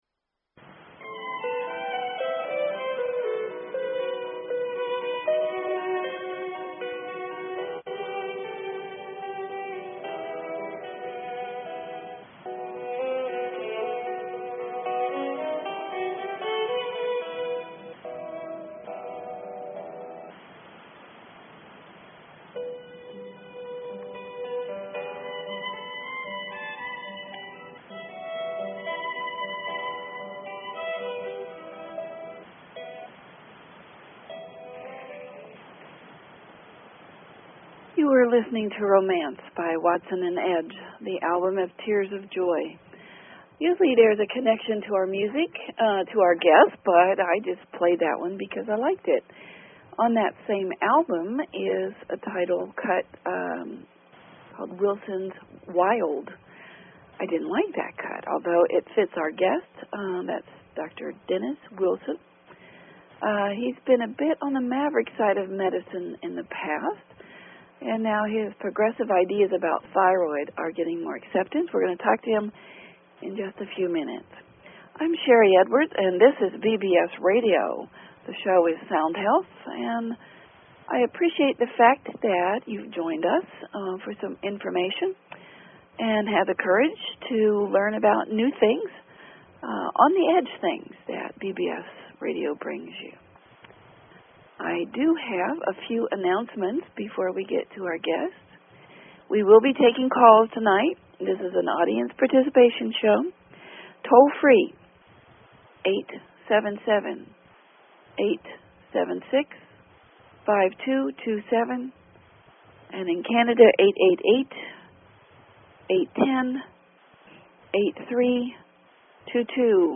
Talk Show Episode, Audio Podcast, Sound_Health and Courtesy of BBS Radio on , show guests , about , categorized as
The show was cut short by a few minutes due to technical difficulties.